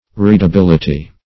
Readability \Read`a*bil"i*ty\ (r[=e]d`[.a]*b[i^]l"[i^]*t[y^]),